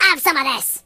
project_files/AudioMono/Sounds/voices/Pirate/Firepunch5.ogg